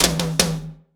ROOM TOM1C.wav